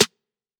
Metro Snare 5.wav